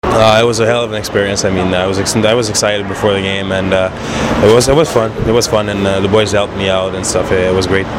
Let’s dive into last night’s post-game interviews!